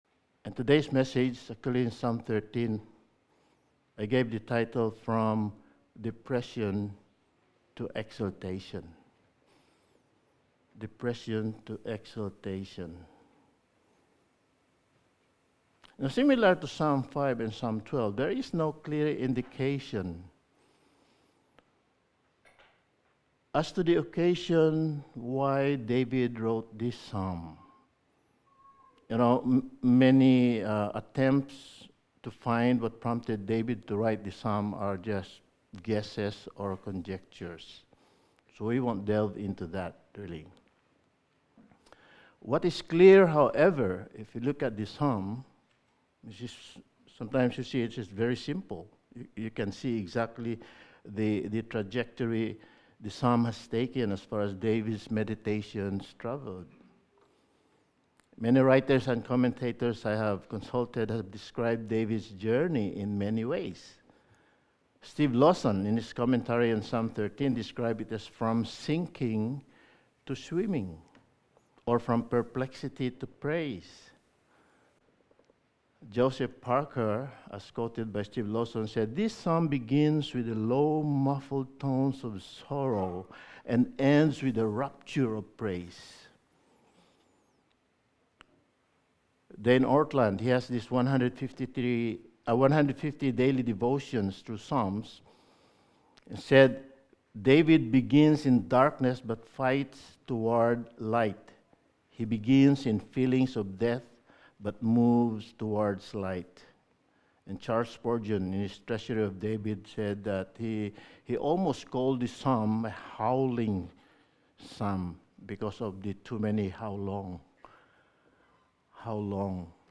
Sermon
Service Type: Sunday Morning